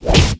attackogg.mp3